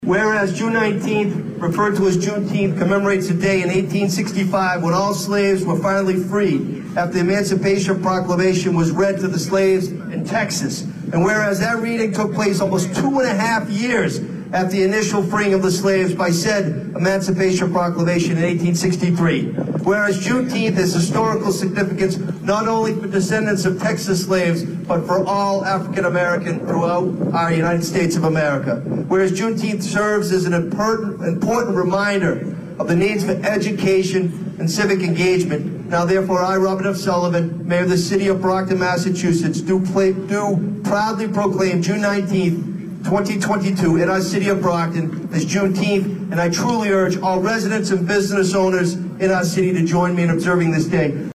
The Black National Anthem kicked off Sunday’s Juneteenth observance at Brockton City Hall, an event which included an Official Proclamation from Mayor Robert Sullivan.
Juneteenth-Proclamation-sullivan.mp3